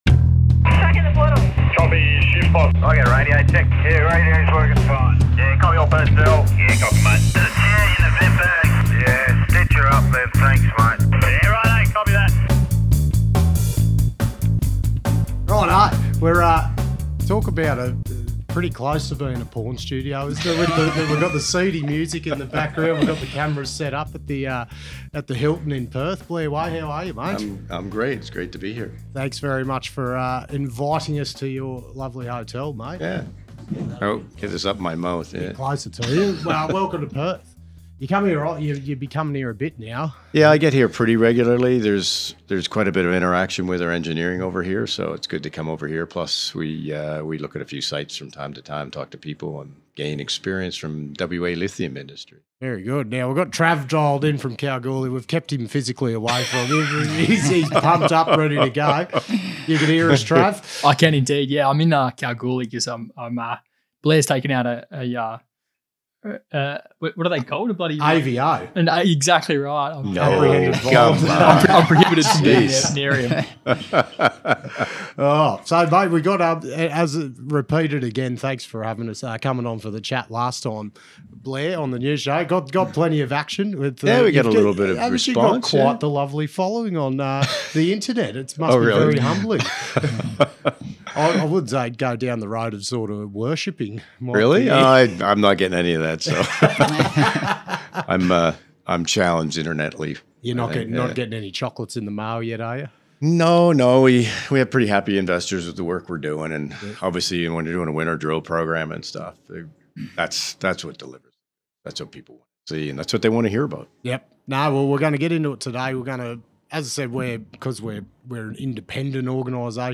joins us LIVE in Perth to follow up on our initial chat. We talk about the retail popularity of the stock, the infamous drill cross sections and much more.